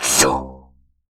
TSK       -L.wav